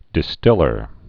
(dĭ-stĭlər)